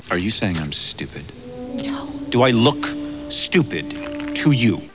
Voice: Kevin Spacey